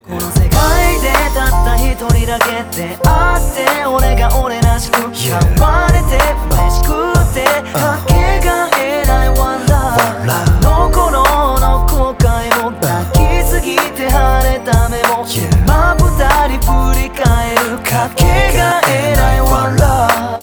分类: MP3铃声
Sad soul music